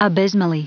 Prononciation du mot abysmally en anglais (fichier audio)
Prononciation du mot : abysmally